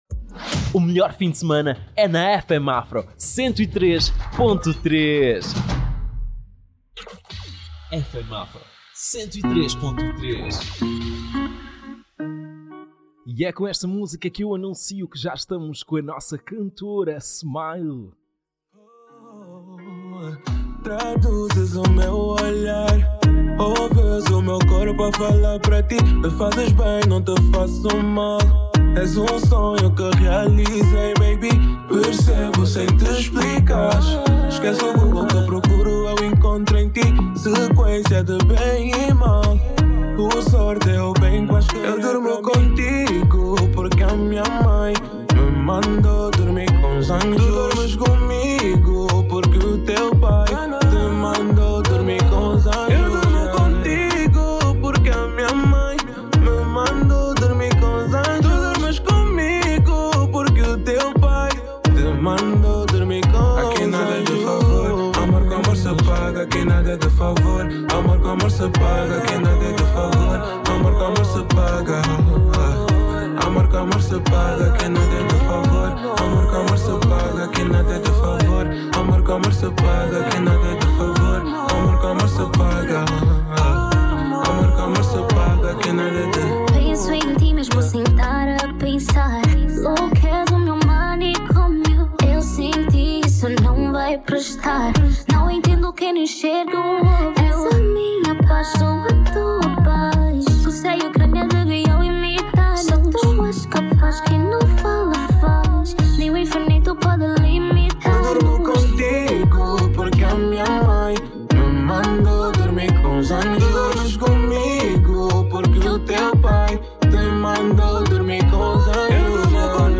Acompanha a entrevista completa com o artista musical